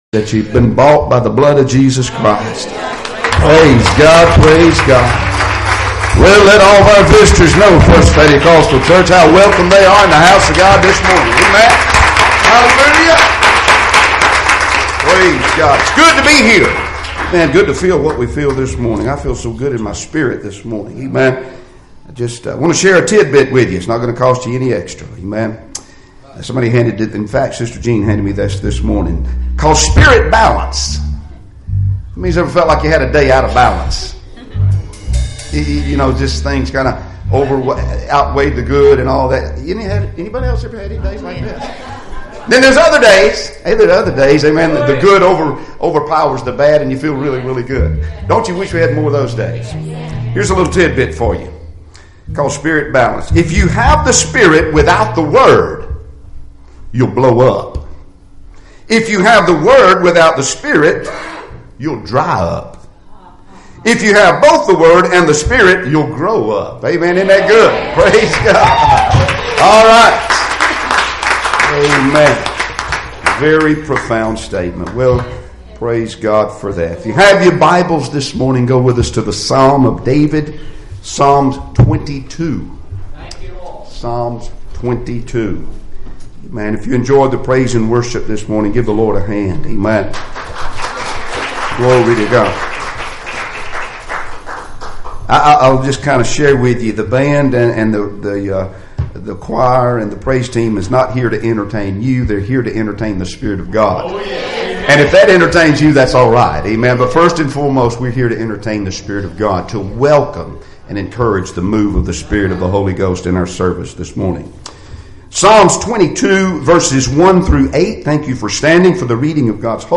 Passage: Psalms 22:1-8 Service Type: Sunday Morning Services